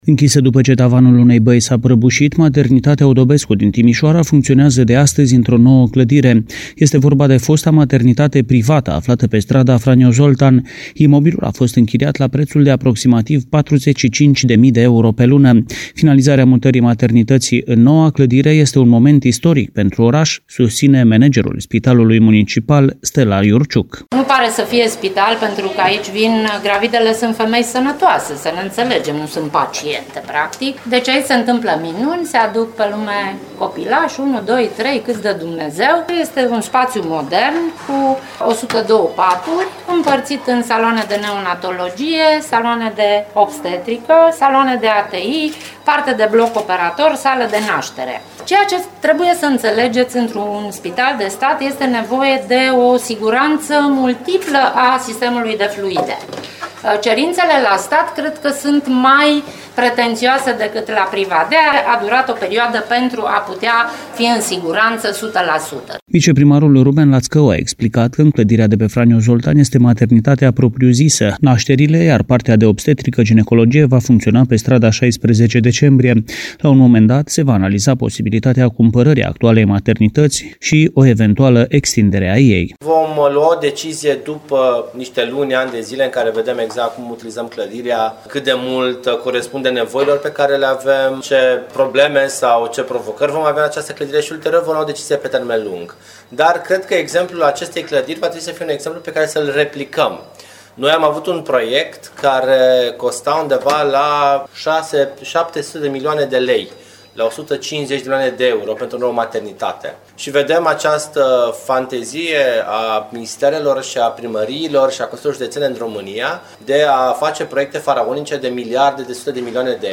Viceprimarul Ruben Lațcău a explicat că în clădirea de pe strada Franyo Zoltan se află maternitatea propriu-zisă, iar partea de obstetrică-ginecologie, va funcționa pe strada 16 Decembrie.